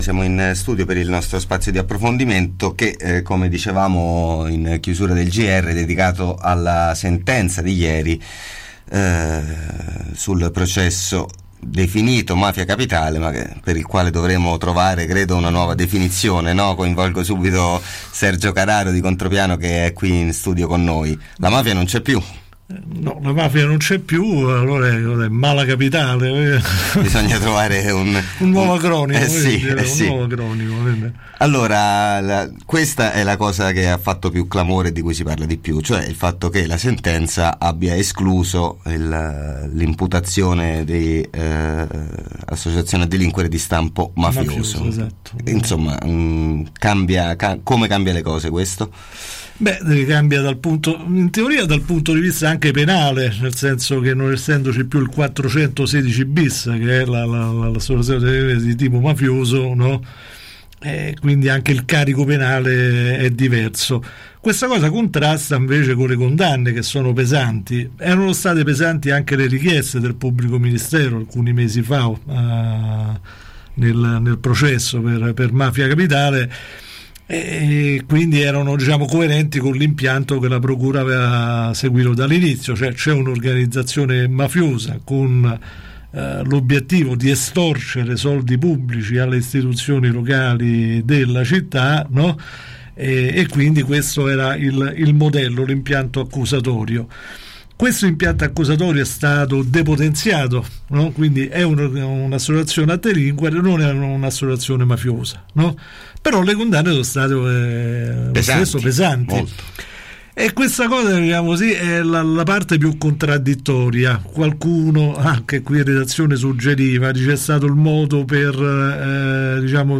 Processo Mafia Capitale: intervista